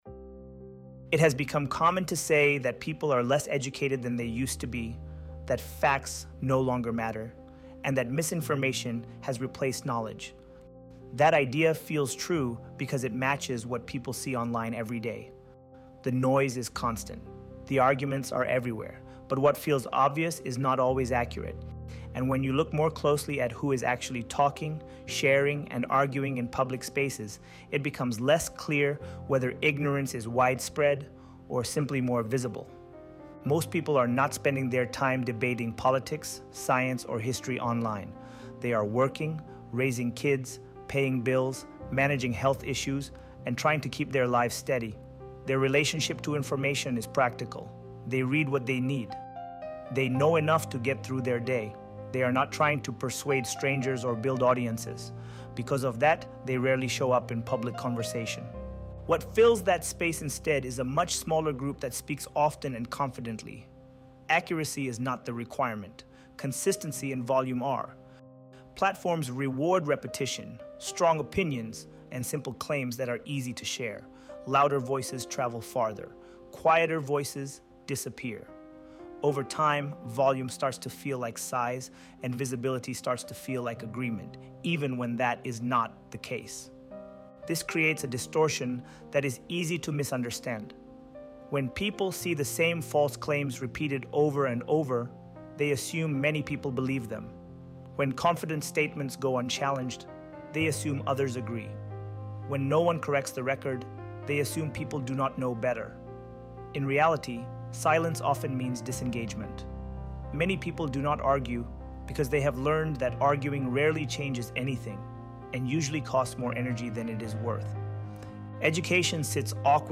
ElevenLabs_Education_Loudness_and_the_Illusion_of_Mass_Ignorance.mp3